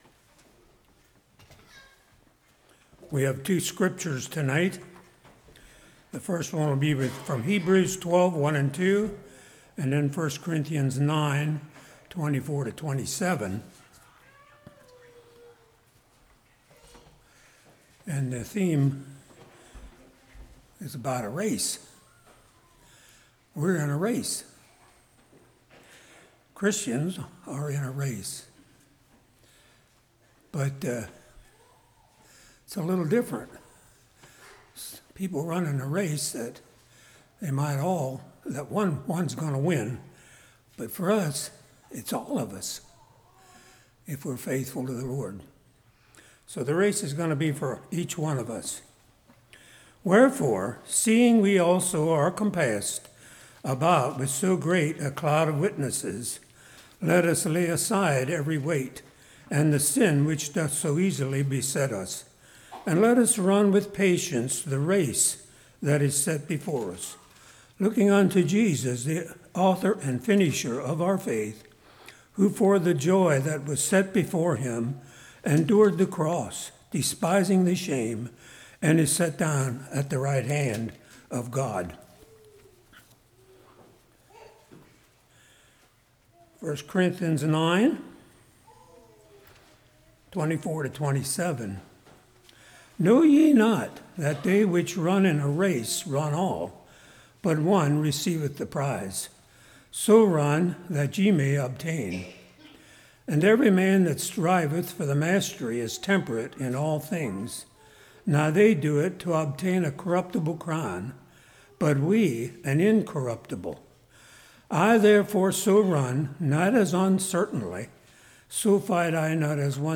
1 Corinthians 9:24-27 Service Type: Revival What is the Race?